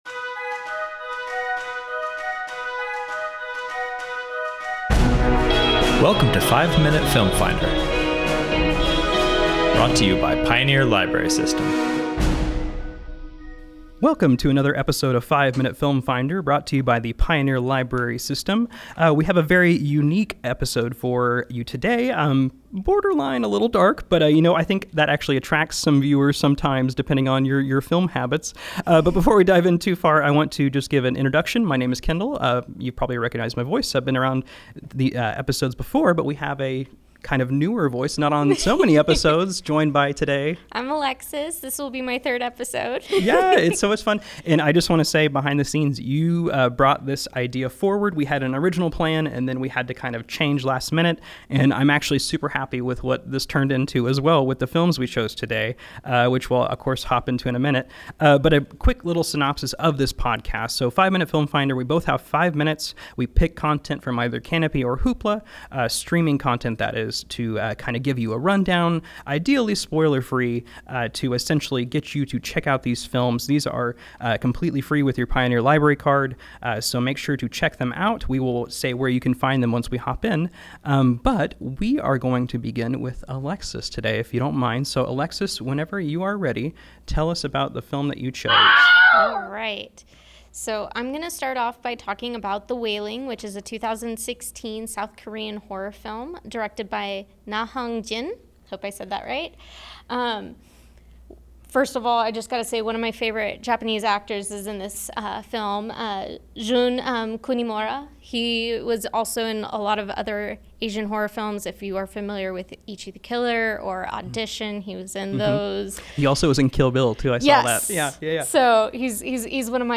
Our hosts have five minutes to inform and sell you on the movies covered in this episode.